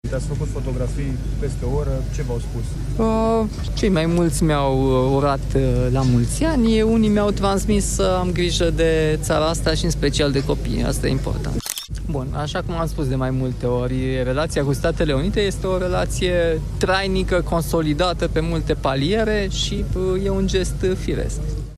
Președintele Nicușor Dan, „baie de mulțime” de peste două ore la Arcul de Triumf – unde mii de oameni au asistat la Parada militară de 1 Decembrie.